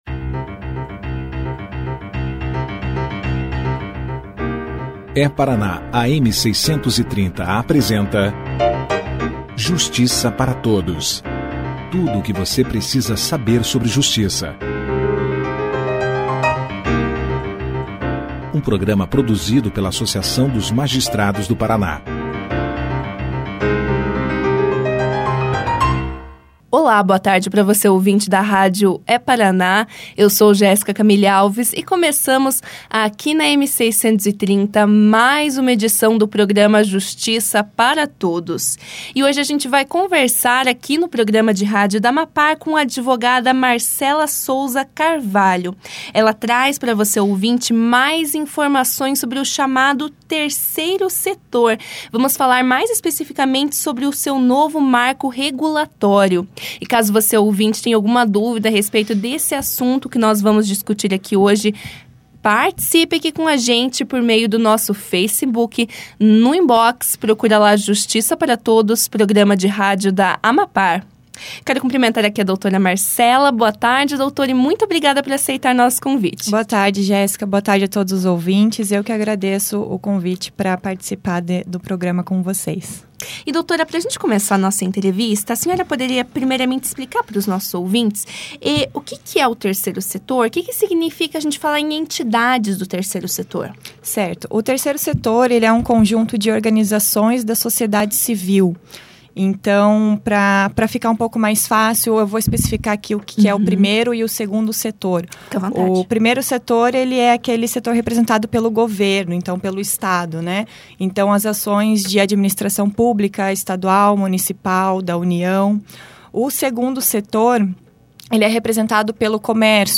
A advogada esclareceu, ainda, dúvidas frequentas relacionadas a questões como remuneração de dirigentes dessas entidades e os procedimentos para abertura de uma organização desse tipo. Confira aqui a entrevista na íntegra.